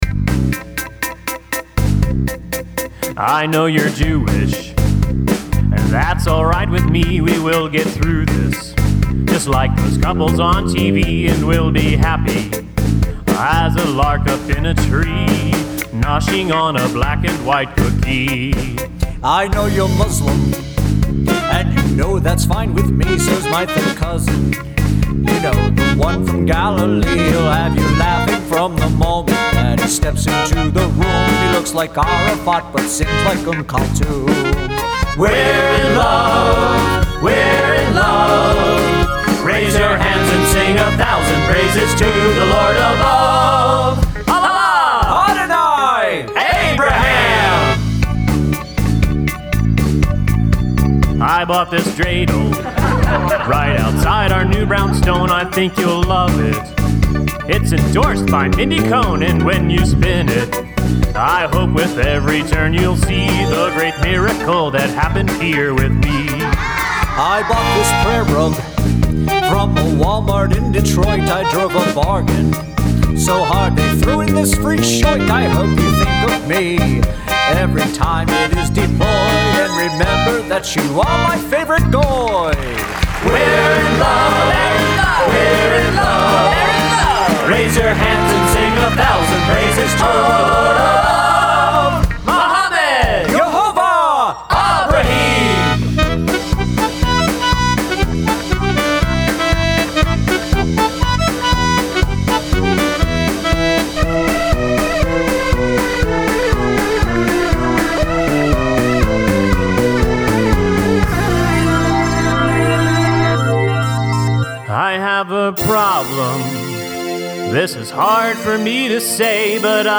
Duet with a guest.